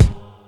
Dre Kick.wav